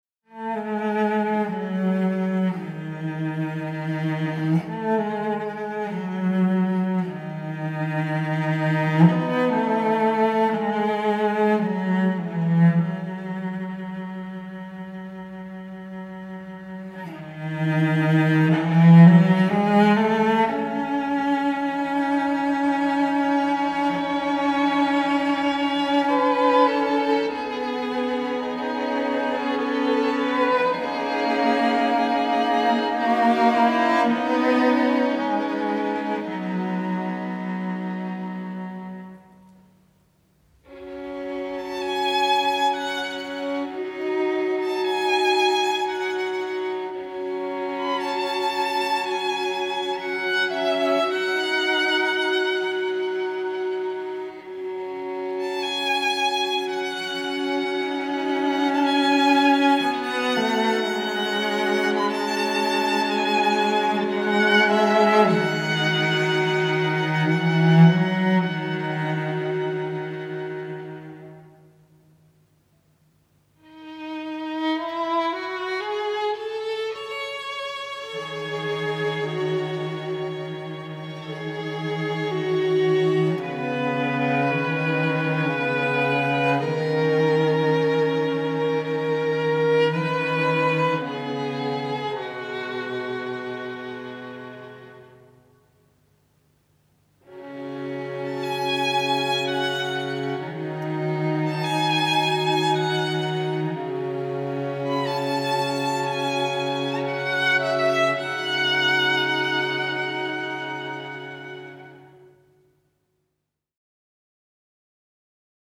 سبک : , بی کلام
حس و حال : عاشقانه